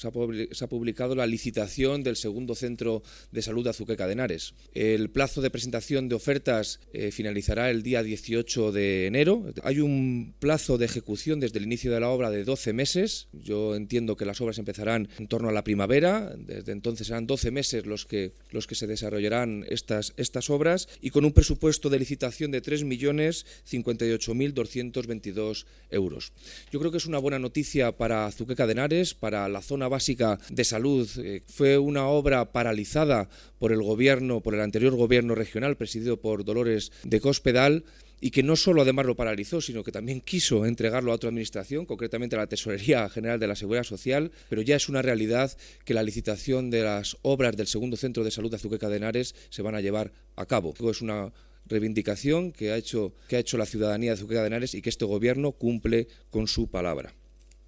El delegado de la Junta en Guadalajara, Alberto Rojo, habla de la publicación en el DOCM de la licitación del segundo centro de salud de Azuqueca.